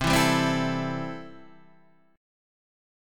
C6 chord